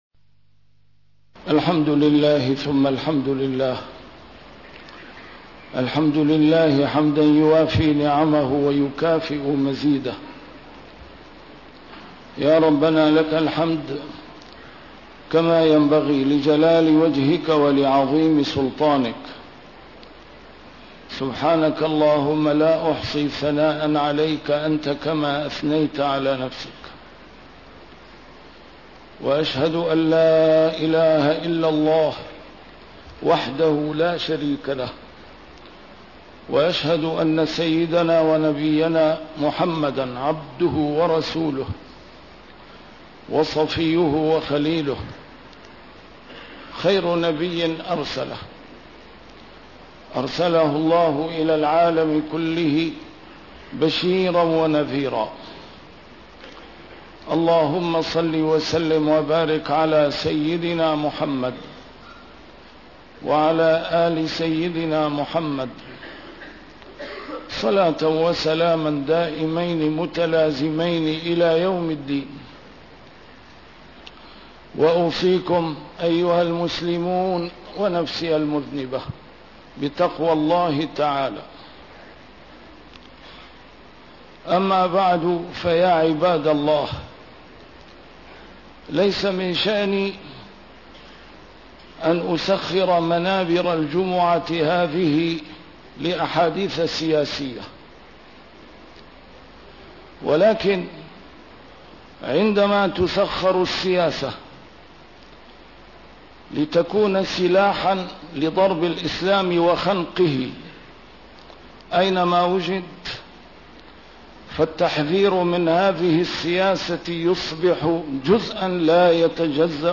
A MARTYR SCHOLAR: IMAM MUHAMMAD SAEED RAMADAN AL-BOUTI - الخطب - الرئيس الأمريكي يعلنها حرباً صليبية في مواجهة الإرهاب!! ومنظمة المؤتمر الإسلامي تنعم بالرقاد!..